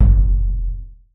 Index of /musicradar/essential-drumkit-samples/Hand Drums Kit
Hand Bass Drum.wav